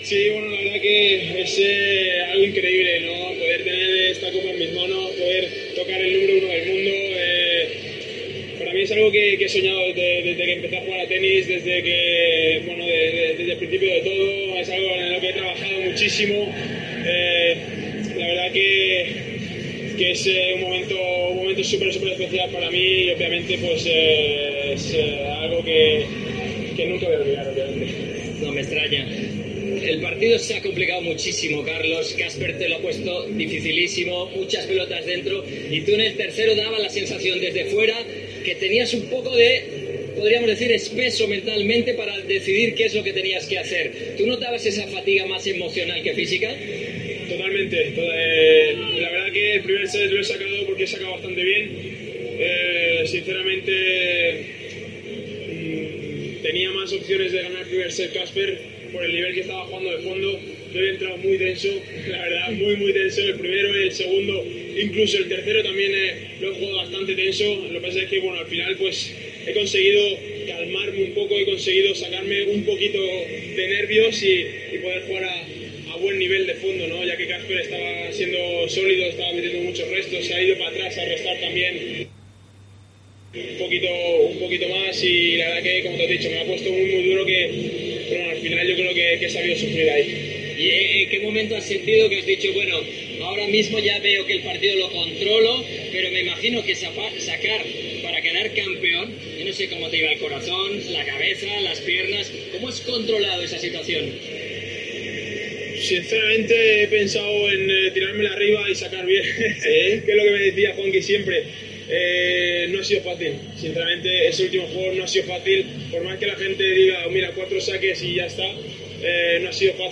Carlos Alcaraz explica sus sentimientos en Eurosport nada más recibir el trofeo